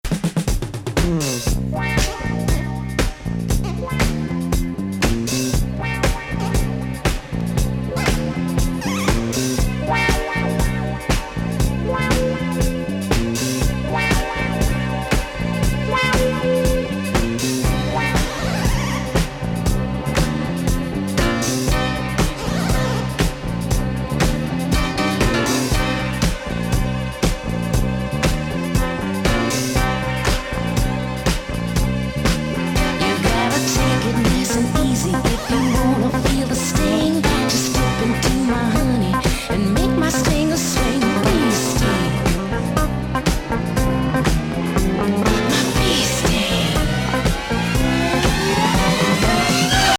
片鱗見え見え賑やかストリングス・ワークにホンノリ・